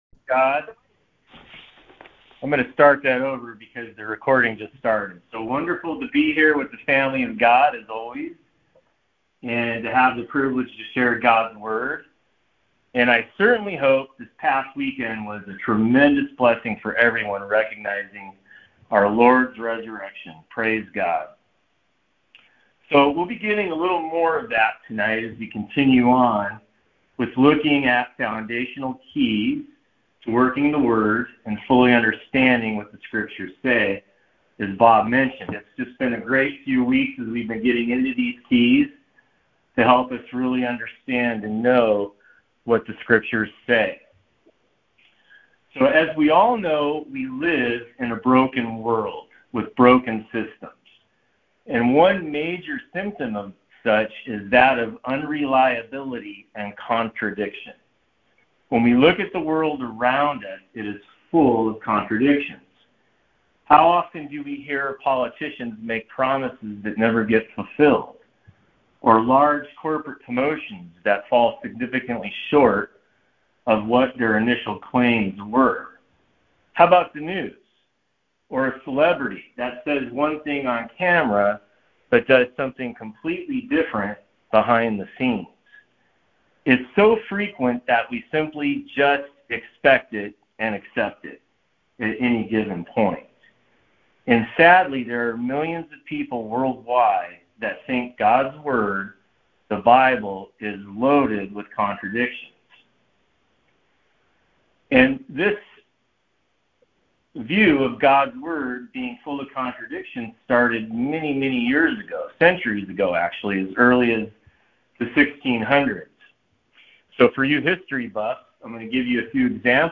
Conference Call Fellowship Date